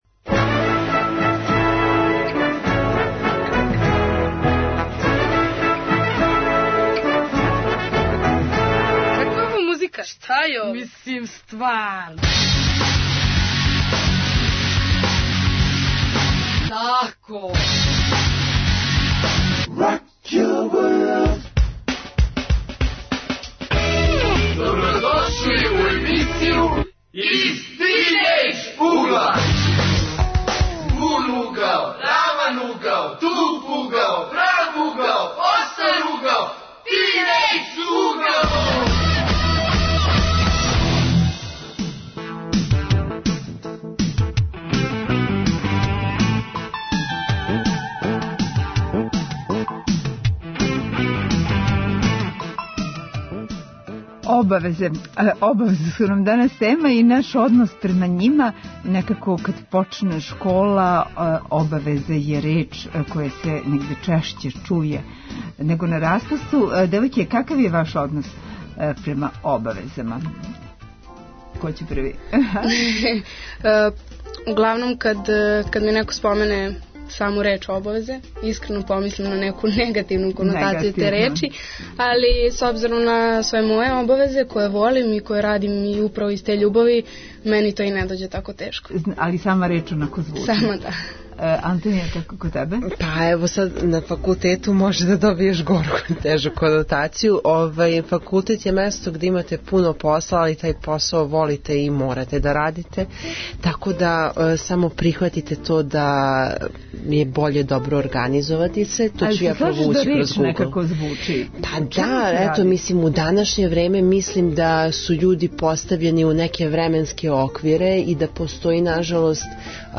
Обавезе и наш однос према њима - тема је данашње емисије. А, како се млади сналазе и какав је њихов однос према обавезама, чућете од наших гостију.